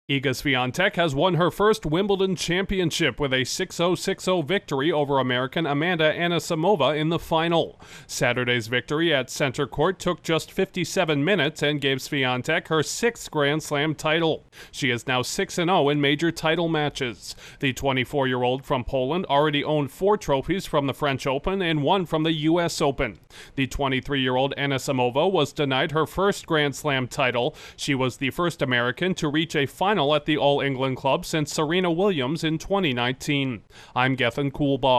The world's No. 4 women's tennis player has claimed her first title at the All England Club, while keeping her major final record intact. Correspondent